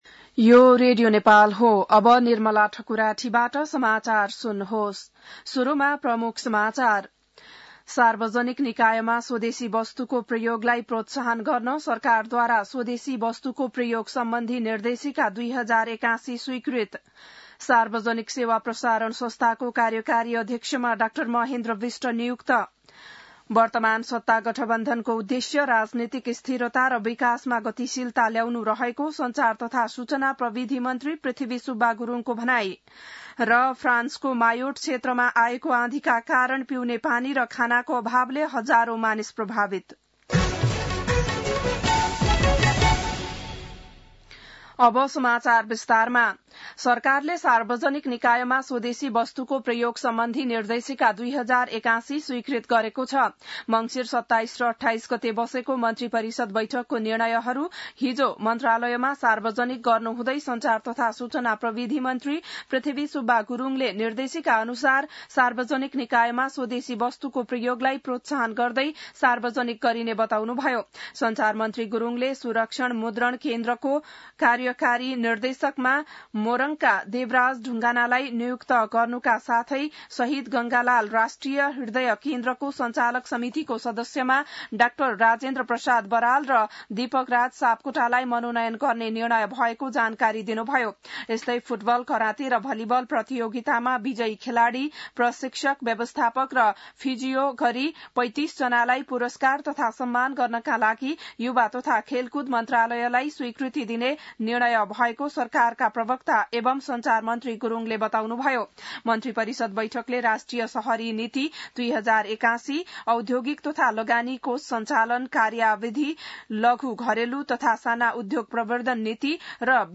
बिहान ९ बजेको नेपाली समाचार : ३ पुष , २०८१